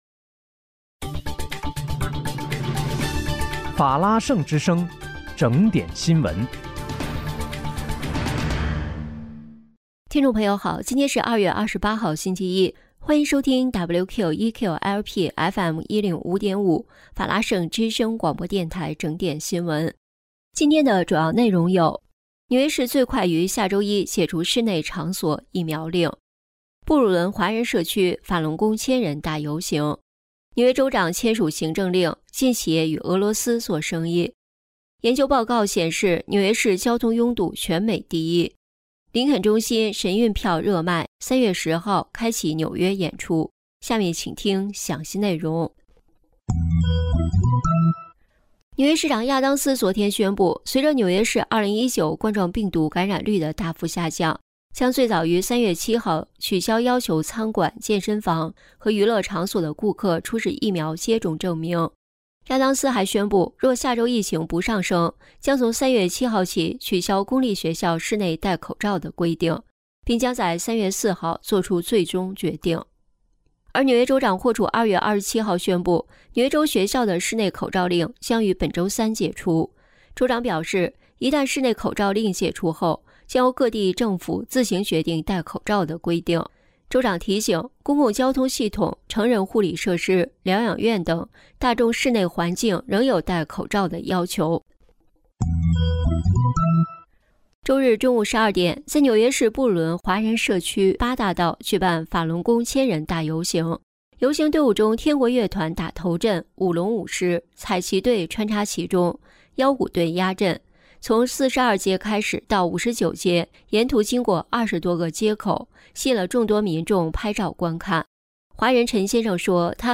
3月28日（星期一）纽约整点新闻